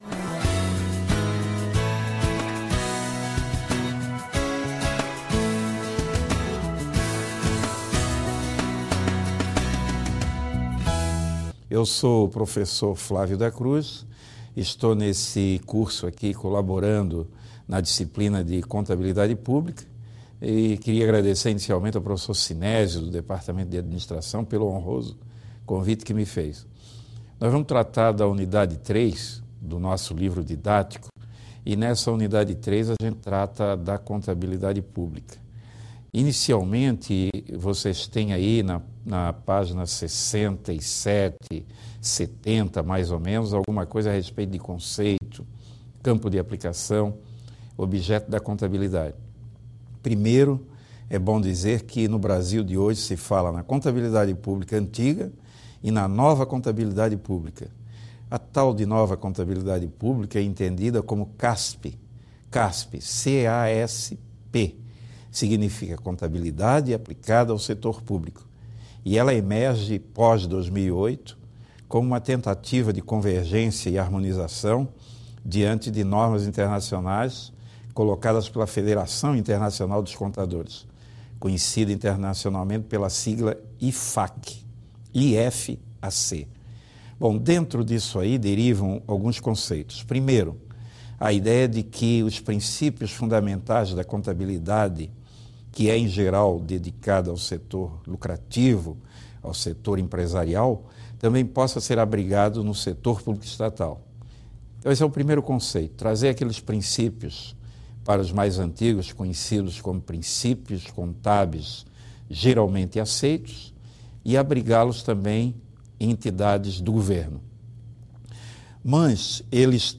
aula3_contab_publica.mp3